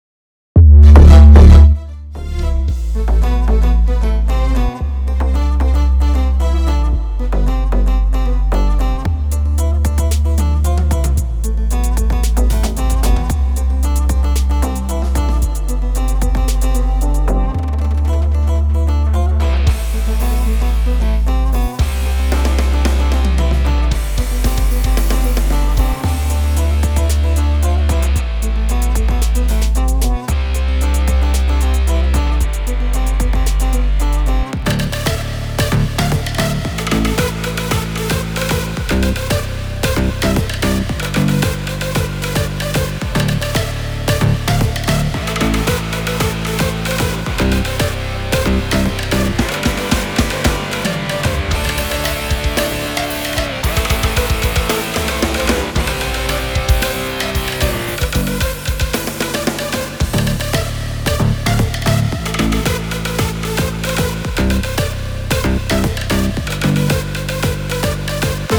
שילוב מענין של רוק ודאנס
שילוב של רוק ודאנס נראה לכם שזה מישתלב יפה?